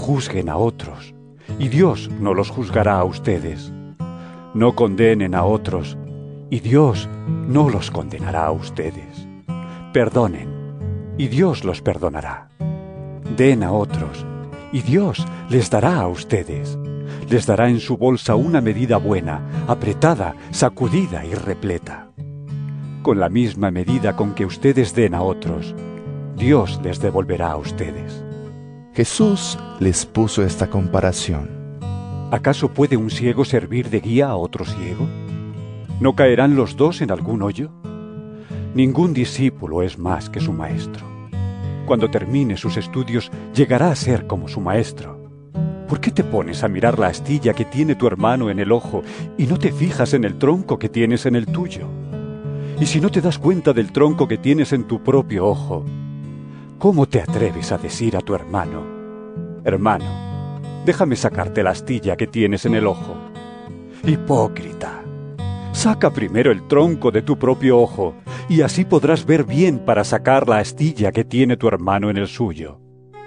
Lc 6 37-42 EVANGELIO EN AUDIO